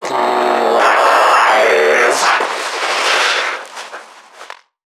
NPC_Creatures_Vocalisations_Infected [124].wav